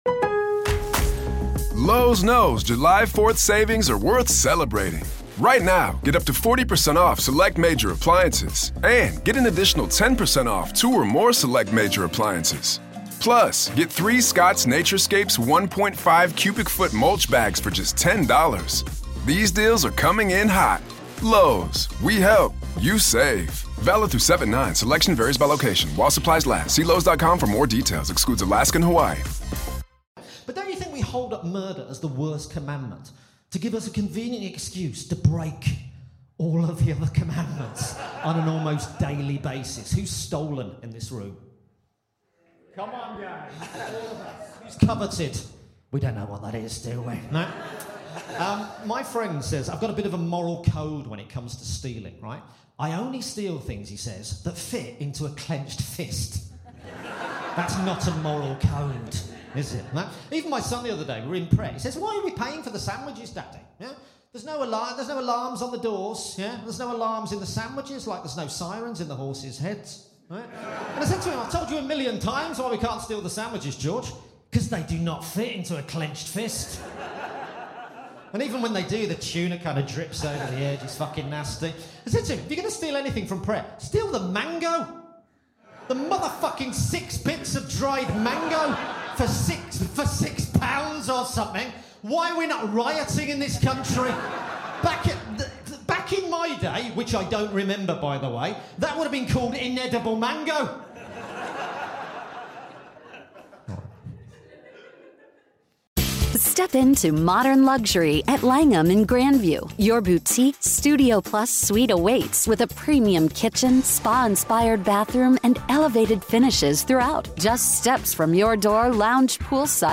Recorded Live at Just The Tonic Edinburgh 2023.